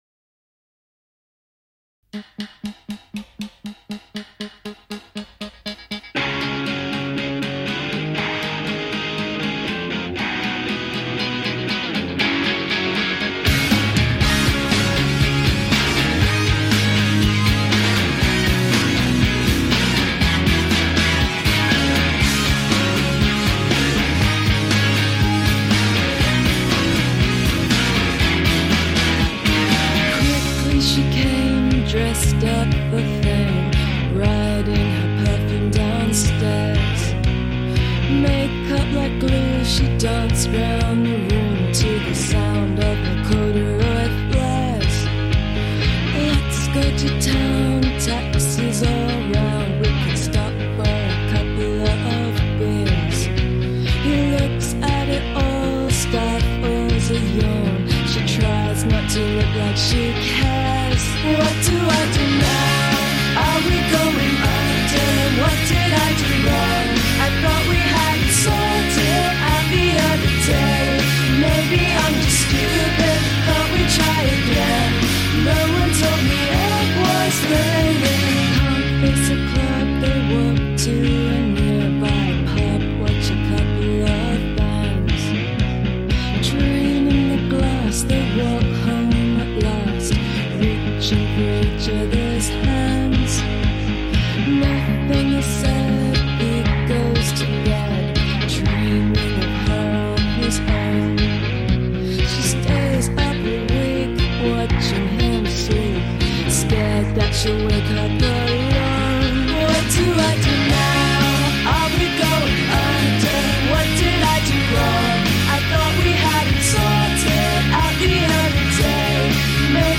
Blondie-lite and inoffensive, I had no need for them.
it has terribly breathy vocals